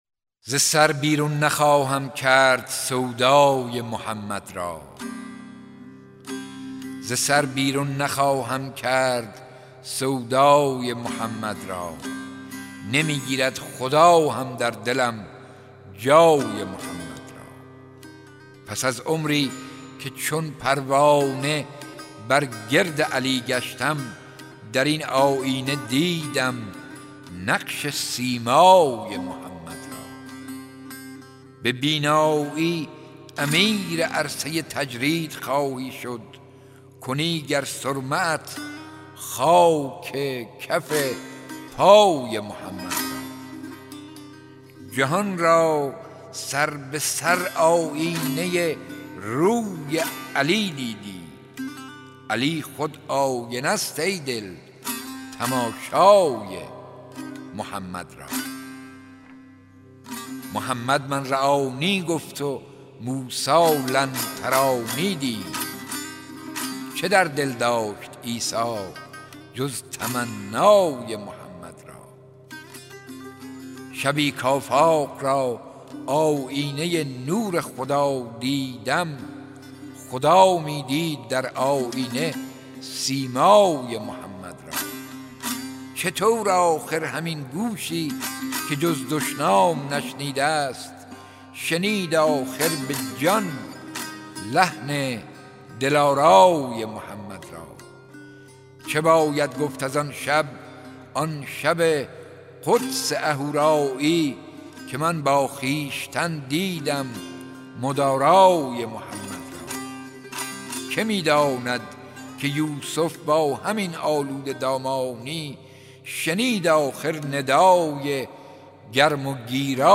دانلود دکلمه داغ سودای محمد (ص) با صدای یوسفعلی میرشکاک
گوینده :   [یوسفعلی میرشکاک]